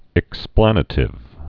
(ĭk-splănə-tĭv)